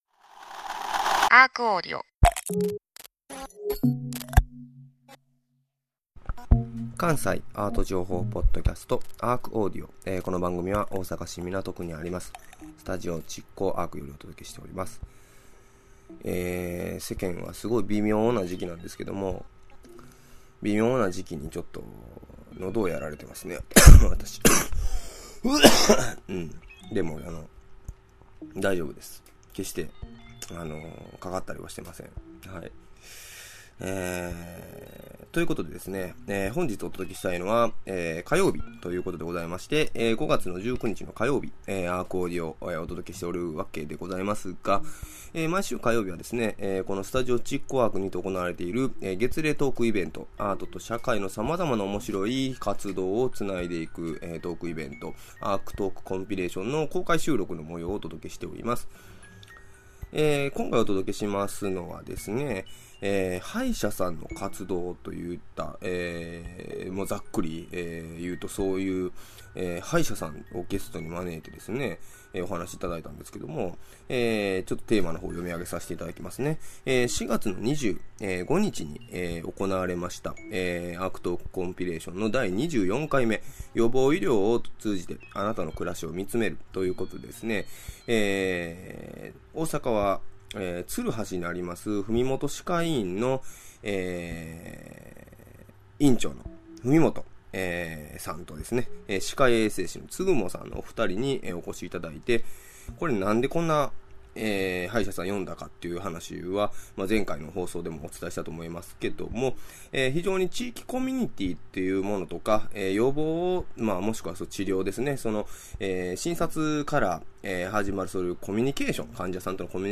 5/19(火) ARCAudio!! トーク「予防医療を通して、あなたの暮らしをみつめる」2/3